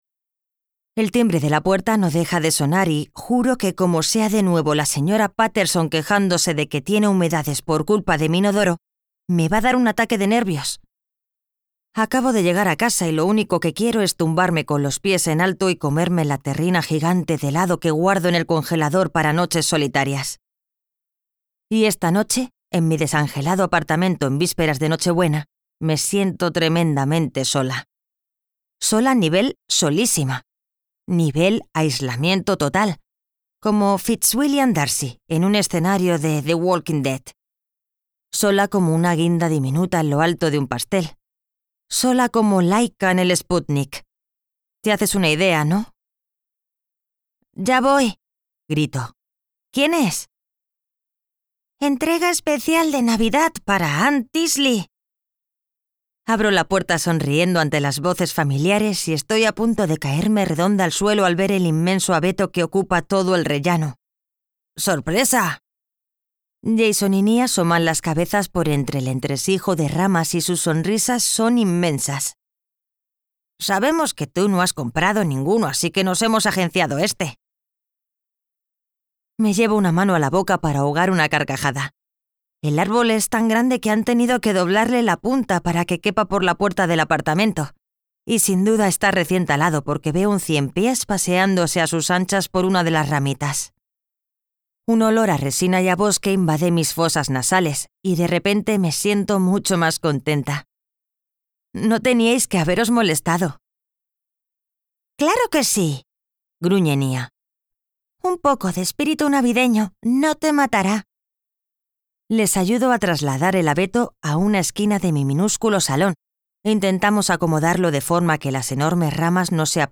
Audiolibro La terquedad de las estrellas (The obstinance of the stars)